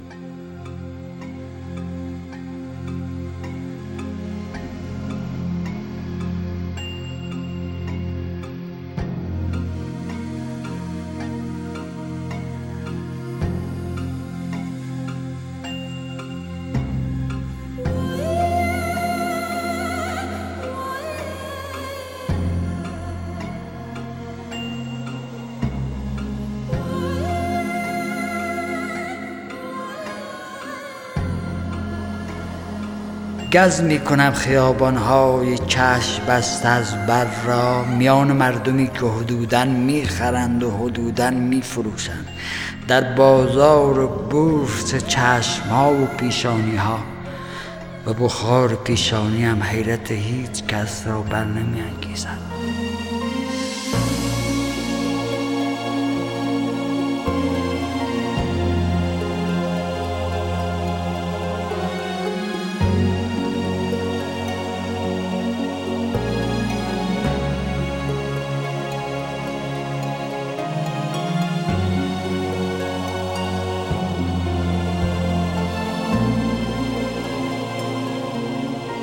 دانلود دکلمه پیاده روی  با صدای حسین پناهی
گوینده :   [حسین پناهی]